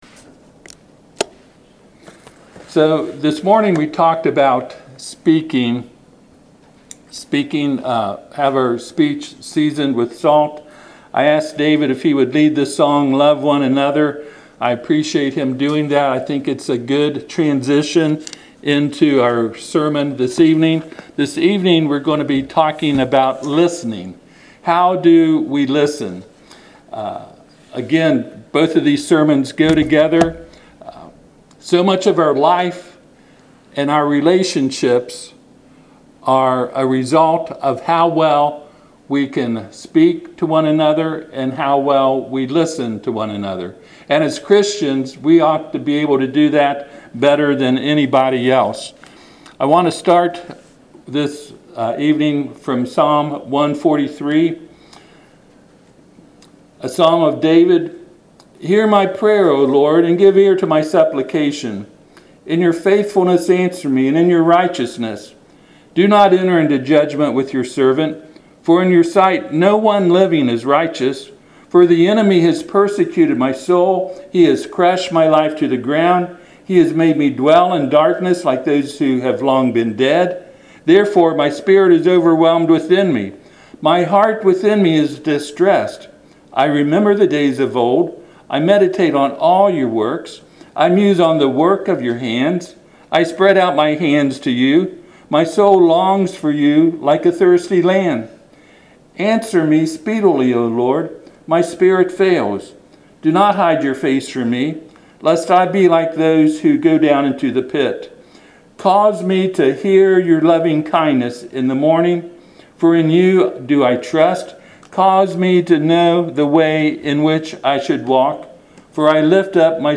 Passage: Psalm 143:1-8 Service Type: Sunday PM